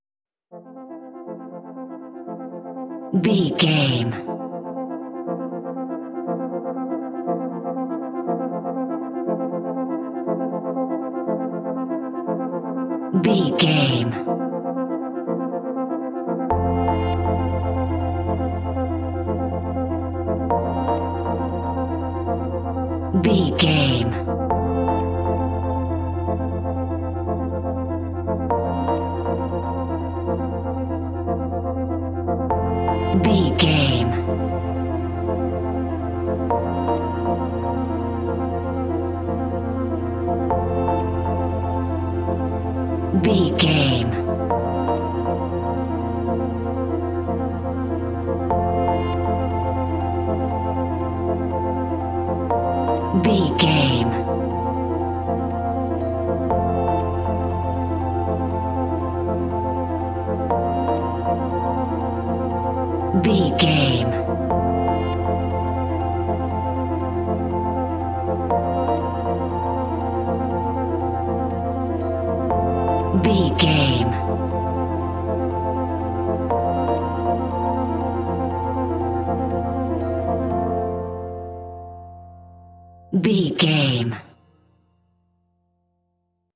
Ionian/Major
ambient
synths
pads
dreamy